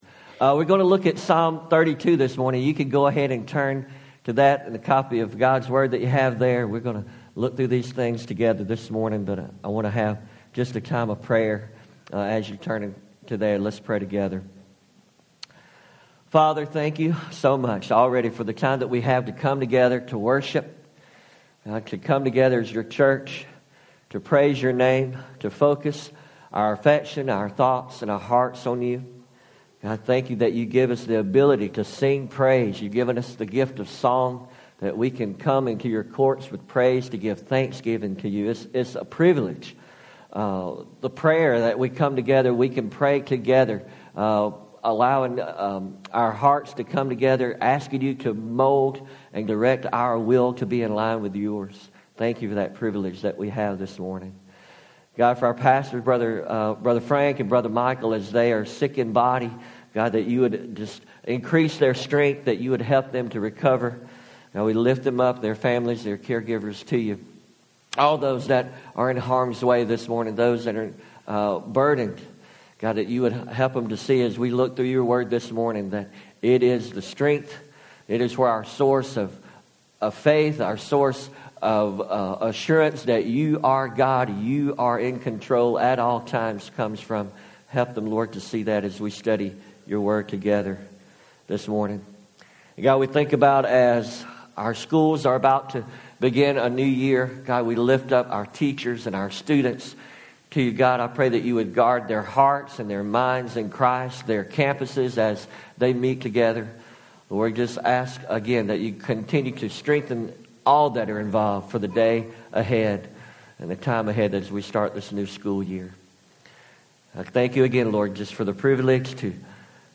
3 Lessons from Psalm 32 Audio Sermon